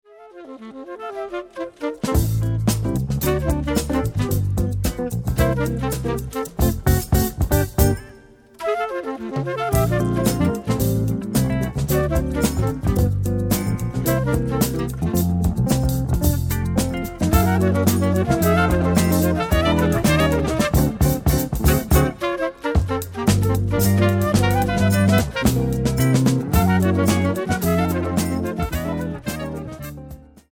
soprano and tenor saxophones
vibraphone, marimba and percussions
harp